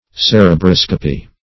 Search Result for " cerebroscopy" : The Collaborative International Dictionary of English v.0.48: Cerebroscopy \Cer`e*bros"co*py\, n. [Cerebrum + -scopy.]